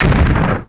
attack1expl.wav